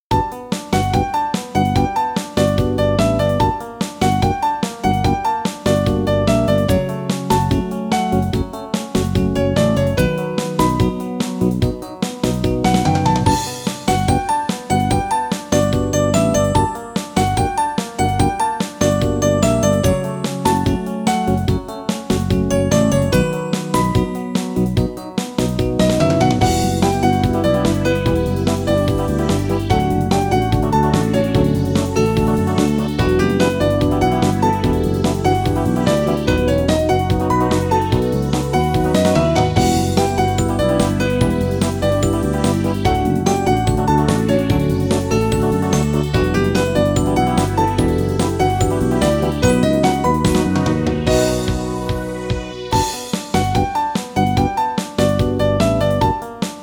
イメージ：POP 明るい   カテゴリ：明るい・日常